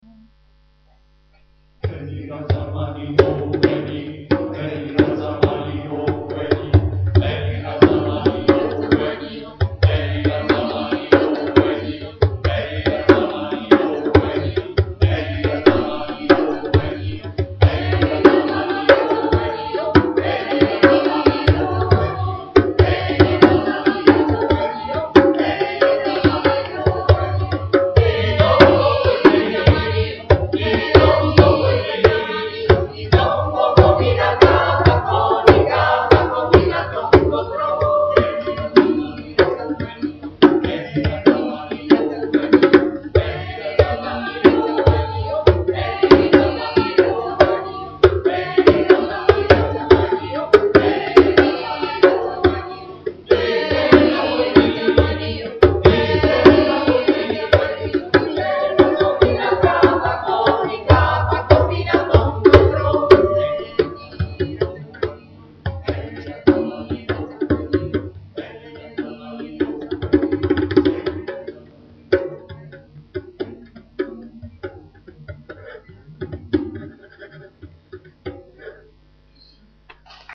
Coro in Maschera - - Recital di Benvenuto in occasione del gemellaggio
Coro in Maschera ,Coro Polifonico fa Musica in Ariccia, Castelli Romani, il repertorio va dalla musica barocca Bach, Emilio de' Cavalieri al gospel ed agli spiritual. Si compone di 2 sezioni una femminile Soprani e Contralti ed una maschile Bassi, Tenori e Baritoni, Coro in Maschera ,Coro in Maschera ,Coro in Maschera ,Coro in Maschera ,Coro in Maschera ,
Registrazione amatoriale